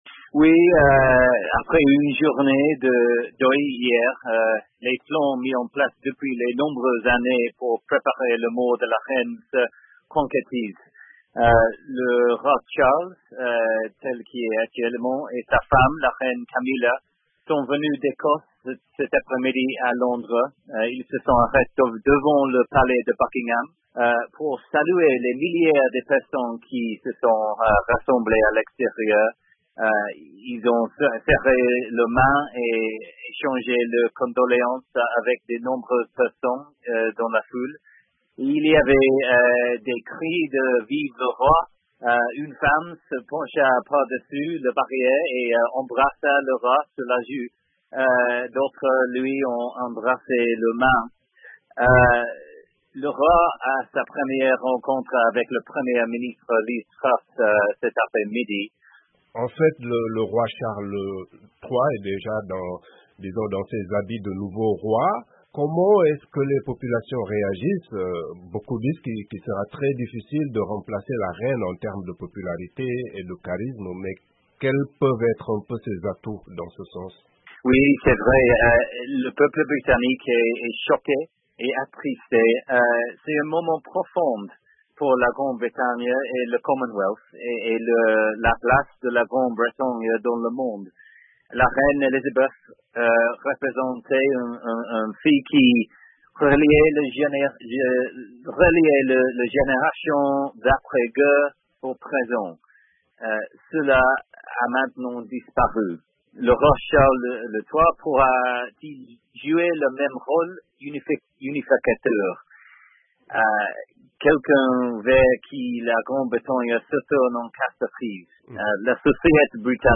En duplex